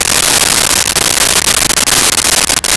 Car Engine Run Loop 02
Car Engine Run Loop 02.wav